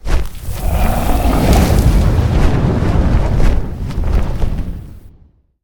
ogg / general / combat / creatures / dragon / plume.ogg
plume.ogg